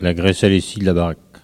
Catégorie Locution ( parler, expression, langue,... )